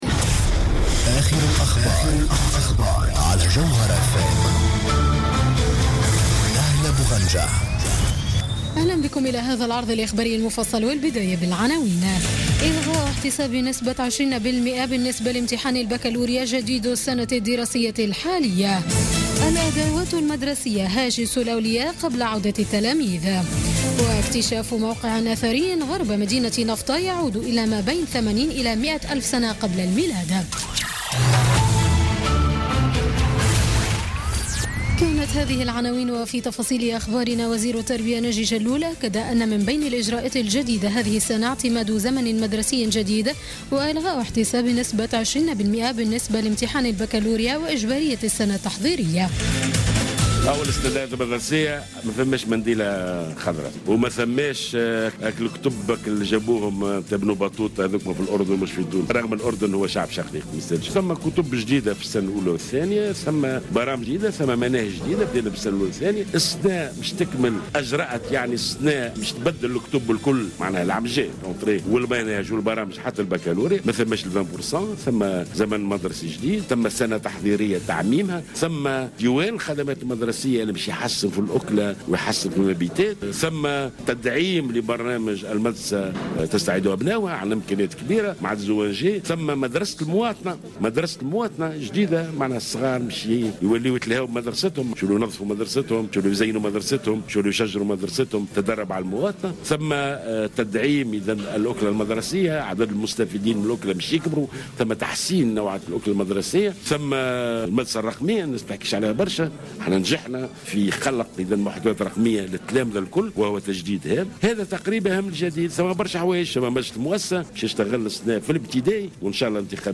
نشرة أخبار السابعة مساء ليوم الأربعاء 14 سبتمبر 2016